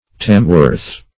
Search Result for " tamworth" : The Collaborative International Dictionary of English v.0.48: Tamworth \Tam"worth\, n. [From Tamworth, Staffordshire, England.]